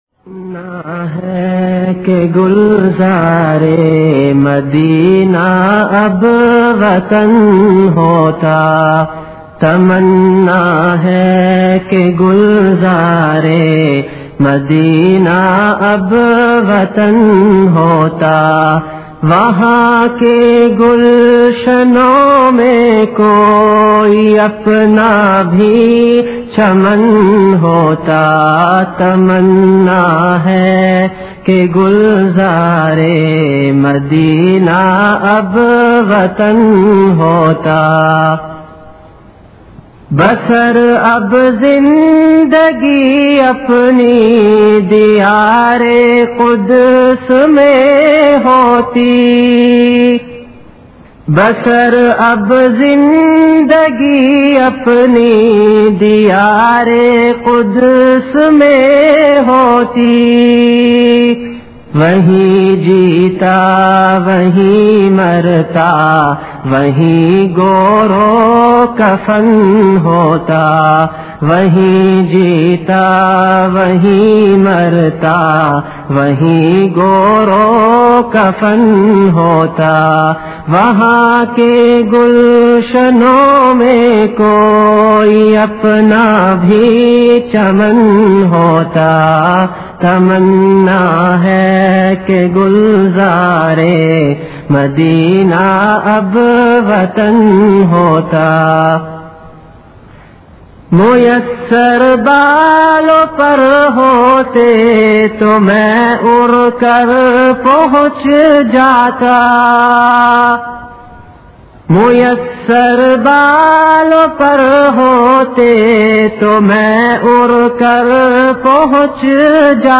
CategoryAshaar
VenueKhanqah Imdadia Ashrafia
Event / TimeAfter Magrib Prayer